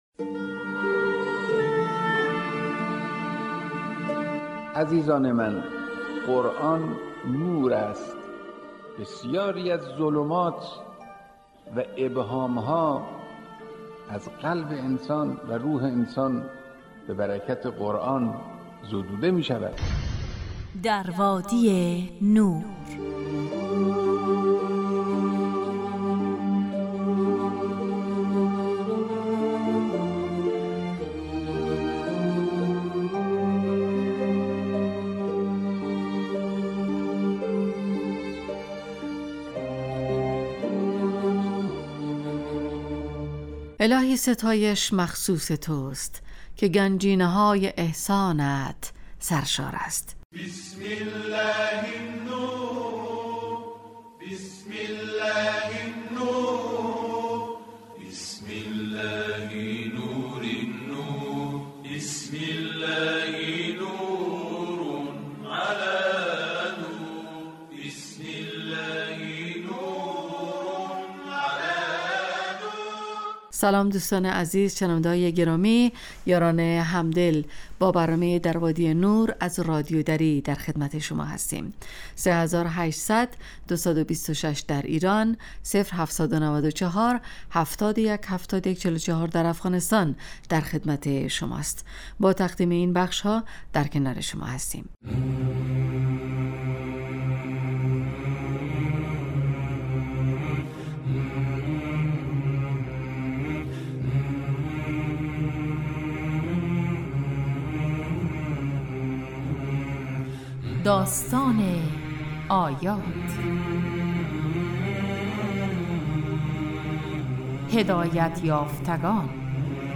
برنامه ای 35 دقیقه ای با موضوعات قرآنی
ایستگاه تلاوت